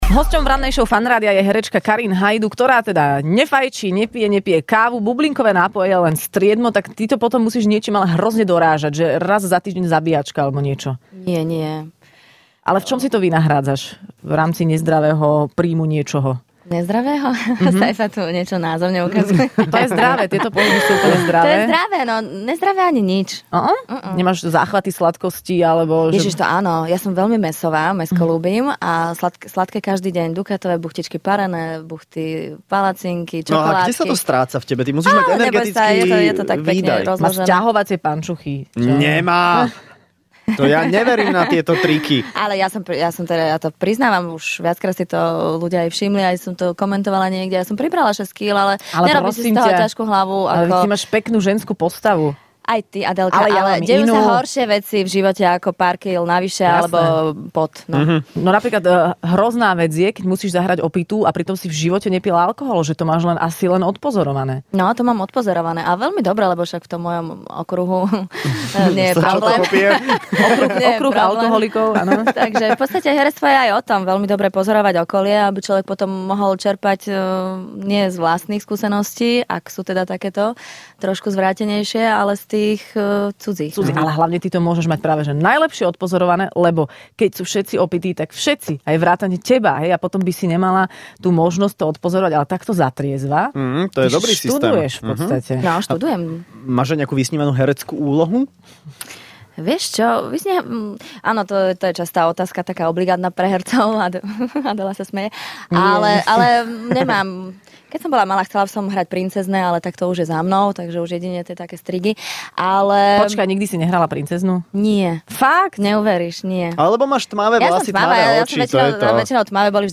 V Rannej šou bola dnes hosťom herečka Karin Haydu.